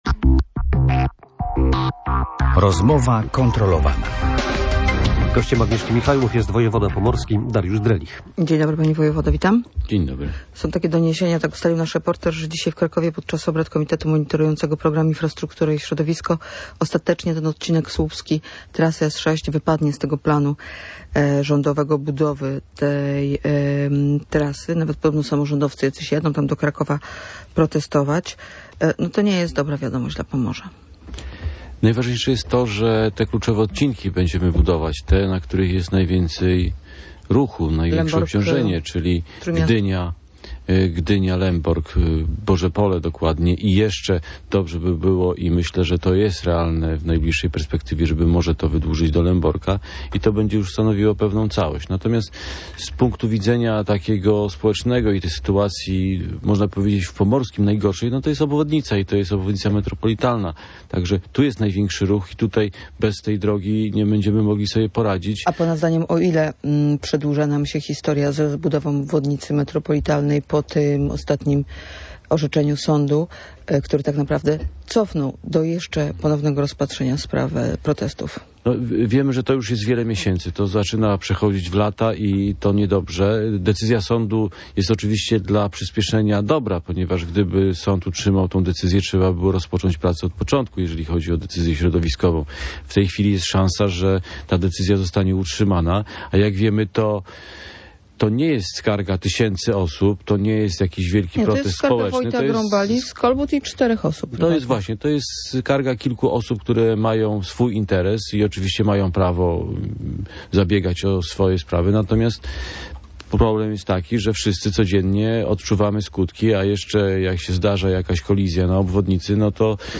Dariusz Drelich był gościem Rozmowy kontrolowanej.